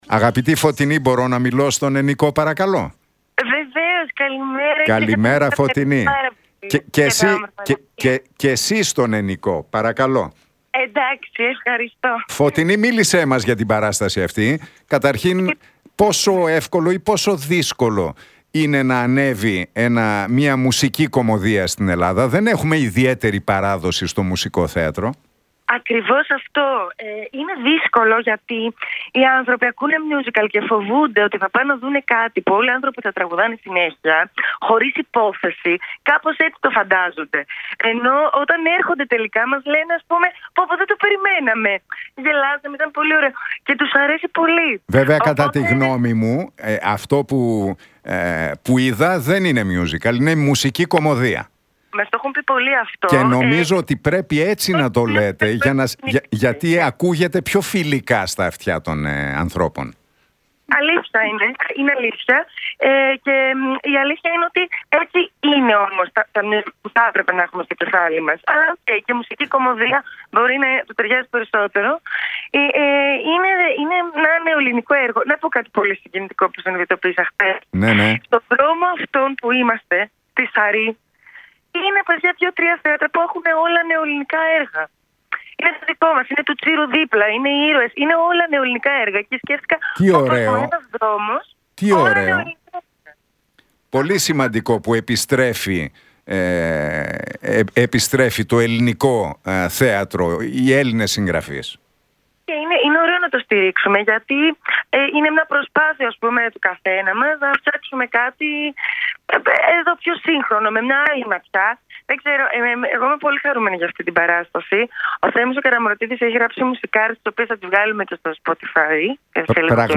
Για το μιούζικαλ, μουσική κωμωδία με τίτλο «Μπα- Μπαμπά» που ανεβαίνει στο θέατρο Ήβη μίλησε η ηθοποιός Φωτεινή Αθερίδου στον Νίκο Χατζηνικολάου από την συχνότητα του Realfm 97,8.